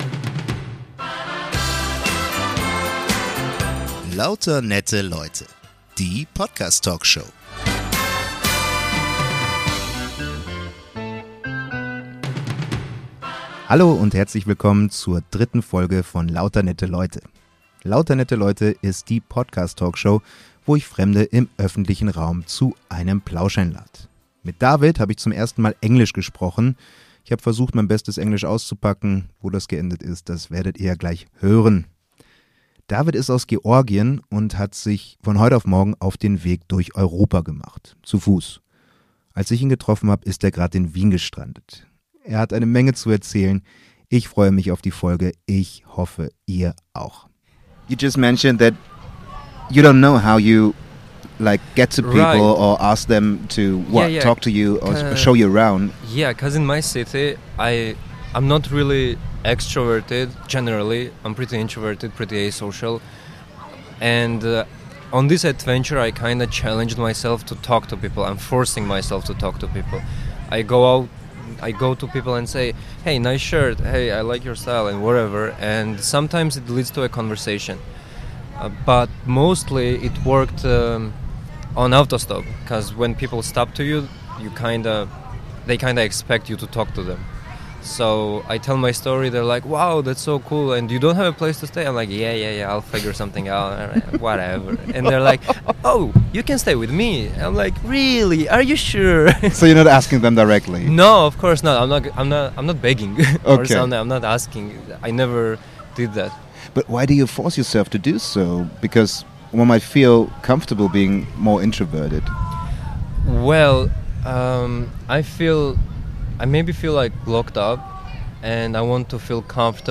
Der erste Plausch auf englisch und dann gleich so ein wilder.